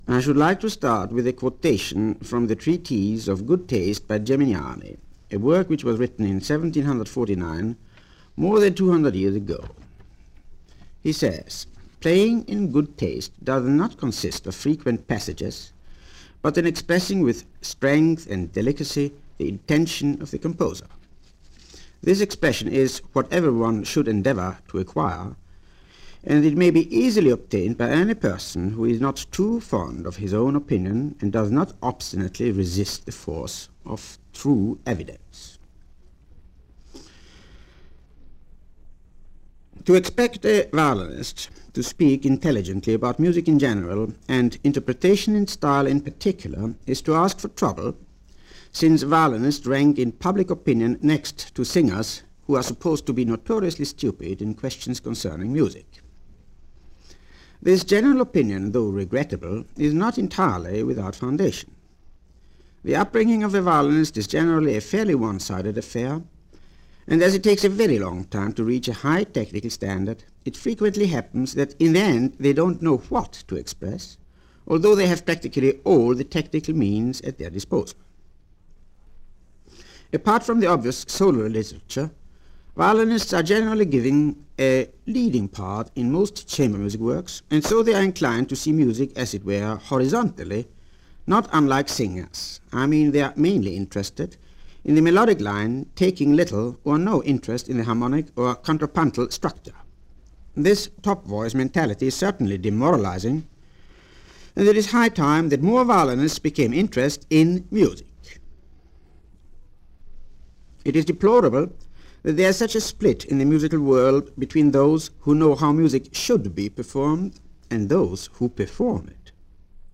The legendary violinist Max Rostal in a pre-recorded lecture given in 1953.